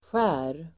Uttal: [sjä:r]